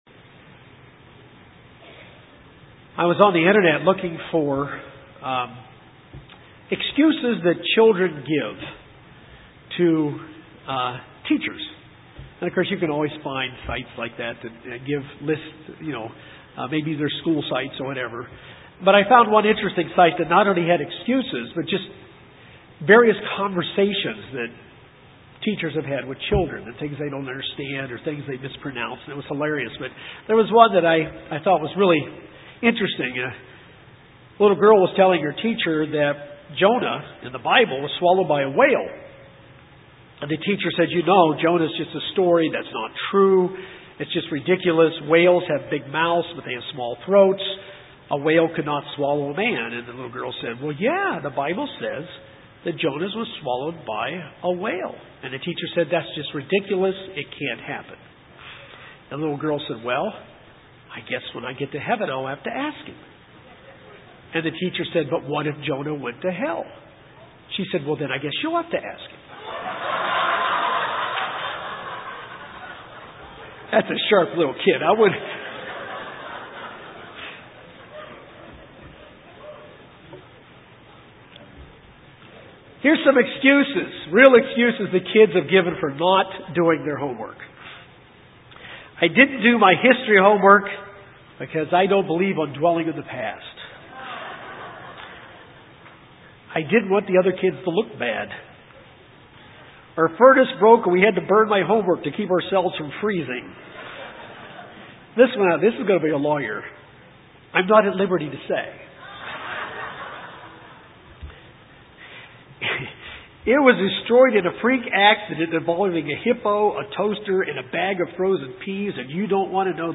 This sermons reviews lessons we can learn from some stories in the bible about situations people were in because of their excuses or their reasons.